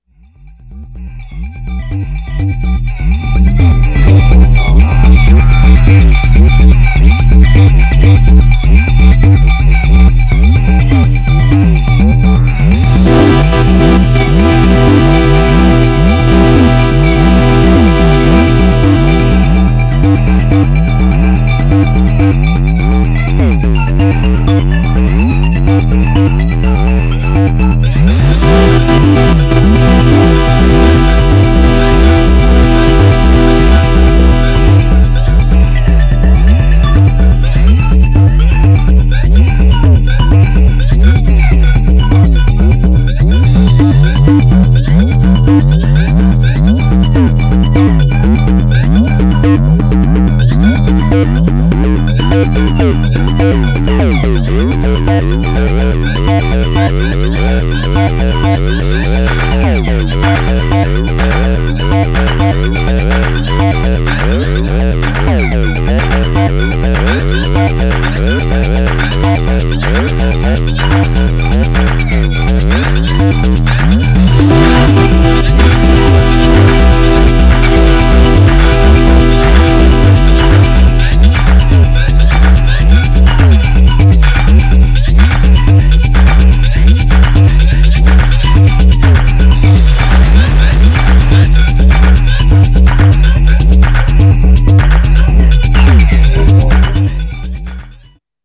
Styl: Dub/Dubstep, House, Techno, Breaks/Breakbeat